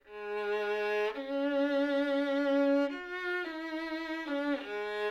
2.2.1 Live recordings: a real player and real violins
We recorded a semi-professional violinist in a large seminar room, playing a short excerpt from the Glazunov concerto (Fig. 1) on the six violins described above.
The position of the player was kept constant relative to the omnidirectional microphone (DPA 2006C) throughout the session, at a distance that had been adjusted at the beginning of the session to obtain pleasant recordings (Audio file Violin5_Live